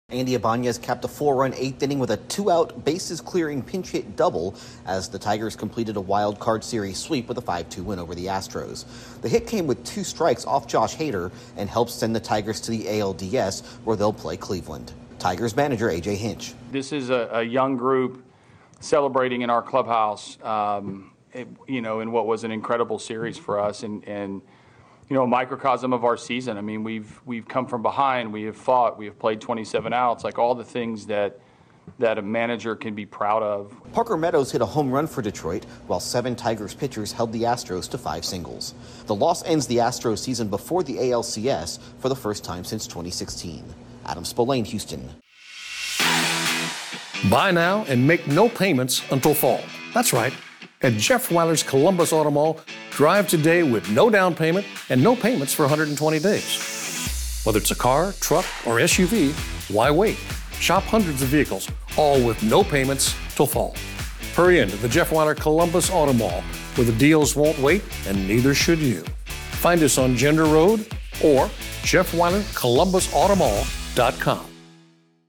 The Tigers take out the Astros in the playoffs. Correspondent